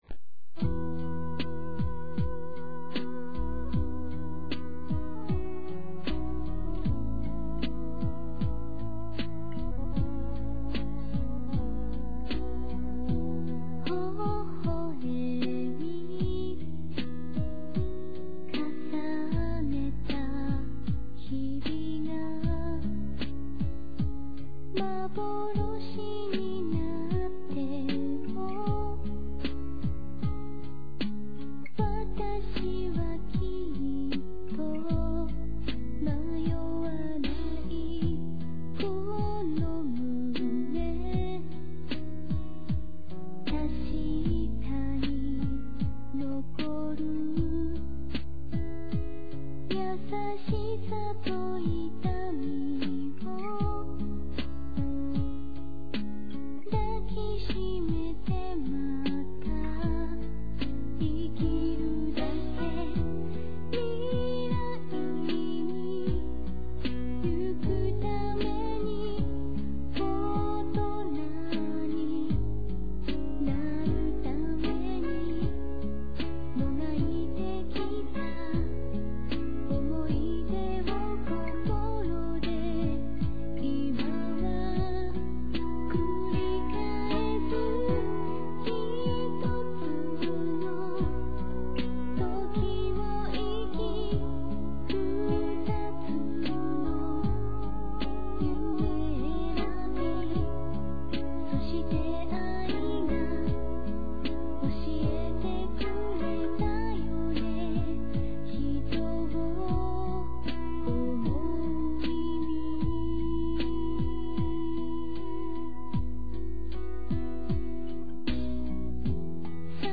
closing song